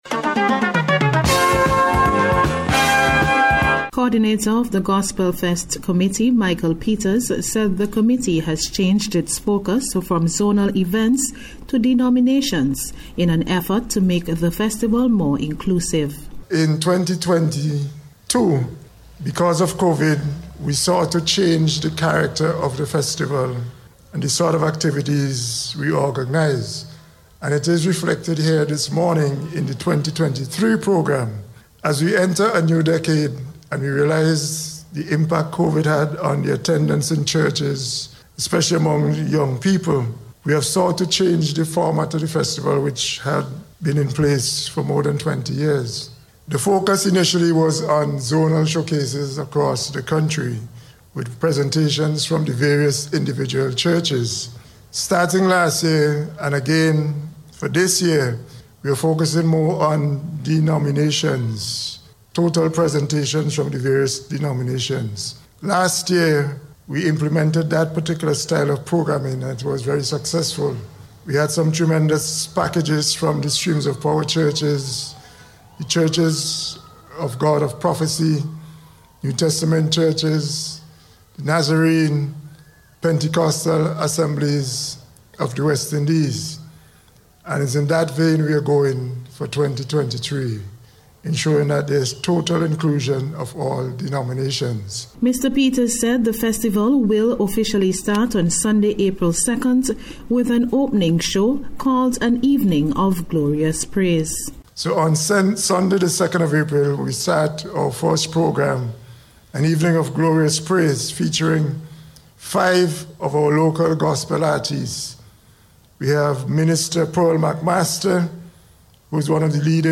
NBC’s Special Report – Wednesday March 29th 2023